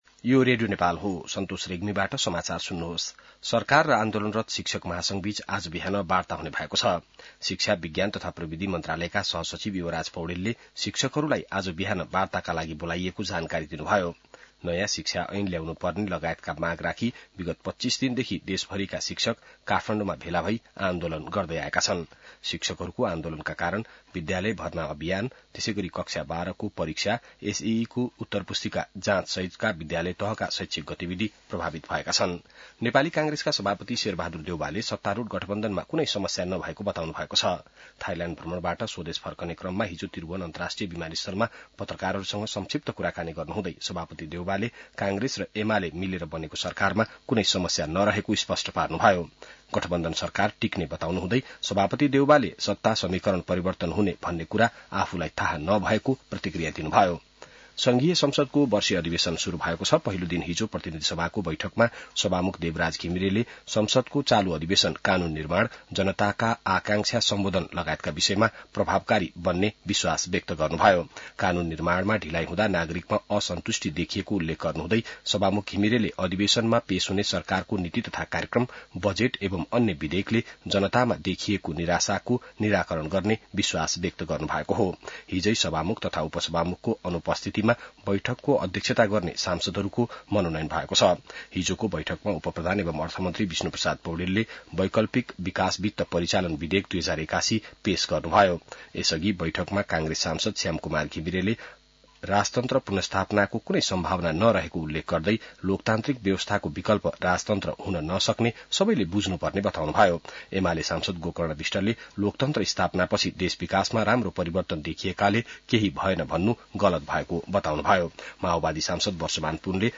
बिहान ६ बजेको नेपाली समाचार : १३ वैशाख , २०८२